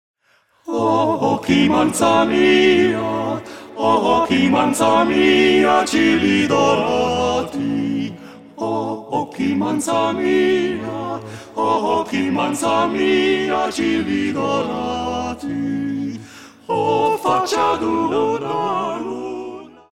grup vocal profesionist
doi tenori, un bariton si un bass.